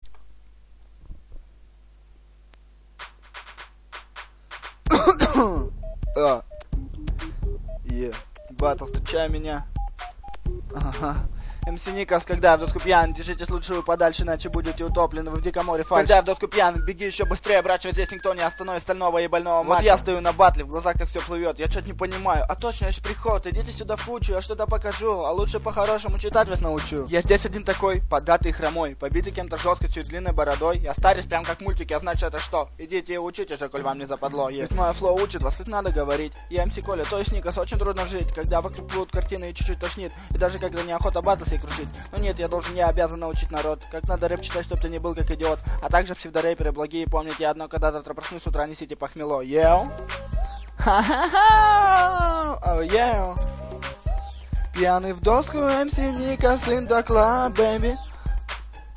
Хип хоп !!
Вот такой трек тема была "Когда я доску пьян" фановый трек такой, без особой смысловой нагрузки